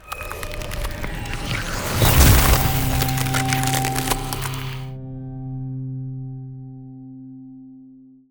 heal.wav